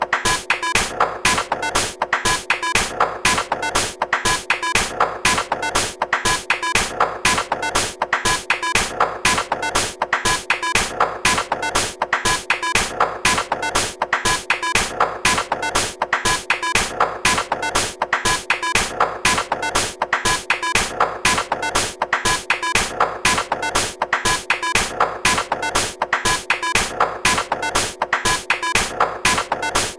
ご用意したのは、ステレオの一般的なループ音源と、POシリーズに同期をして楽しめるクリック入りのシンクモードのループ音源です。
OA機器によるパーカッションサウンド
BPM 120（DISCOモード）